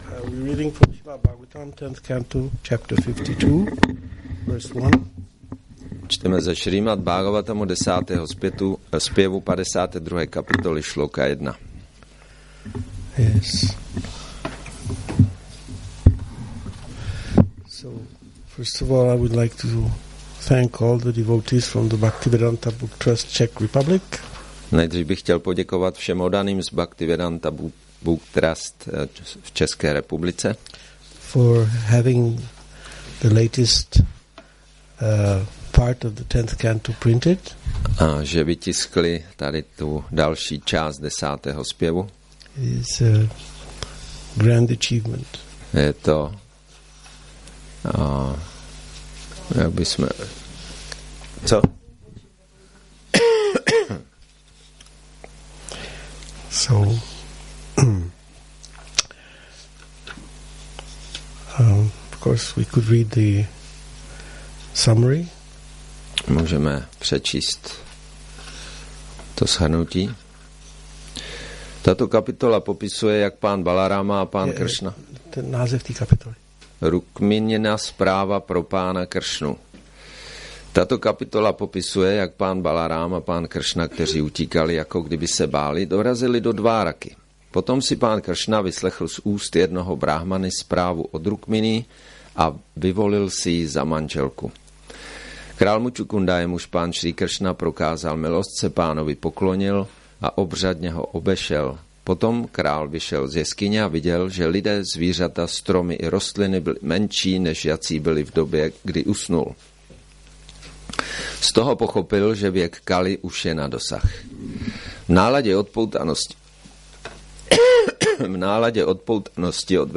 Přednáška